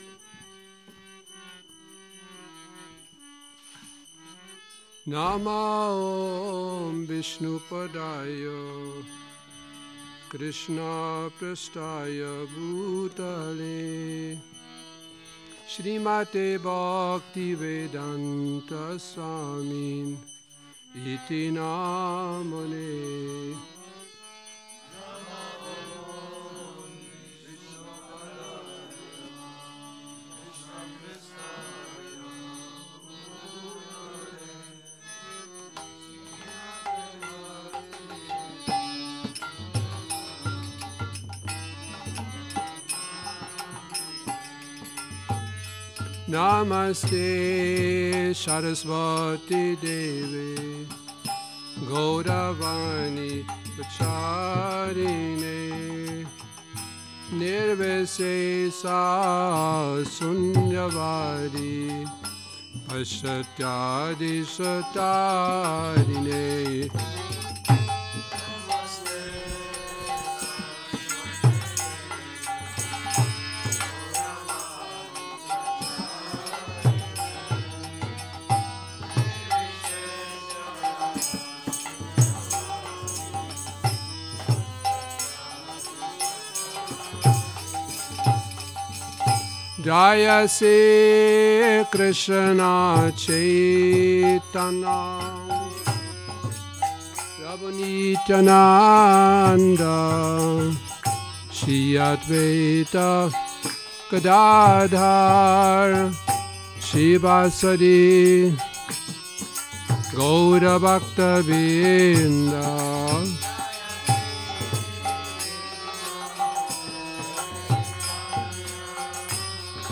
Šrí Šrí Nitái Navadvípačandra mandir
Kírtan Nedělní program